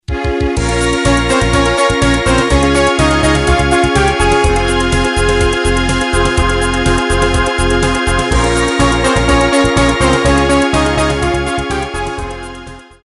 • Пример мелодии содержит искажения (писк).